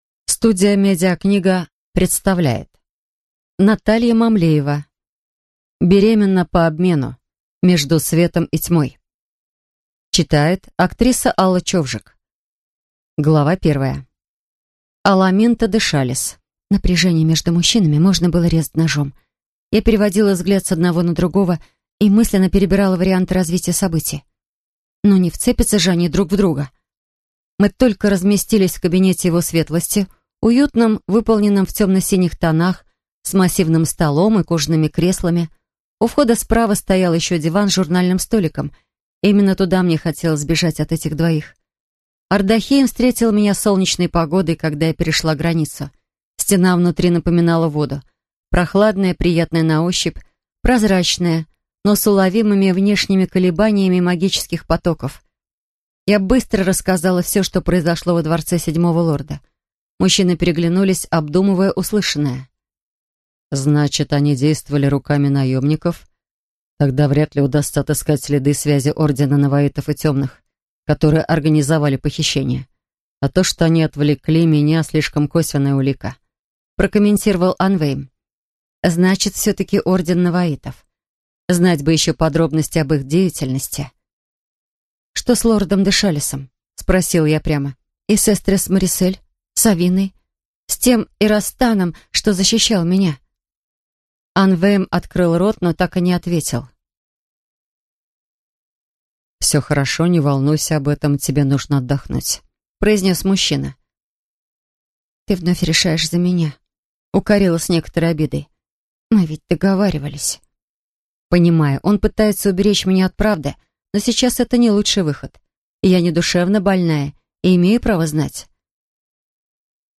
Аудиокнига Беременна по обмену. Часть 2 | Библиотека аудиокниг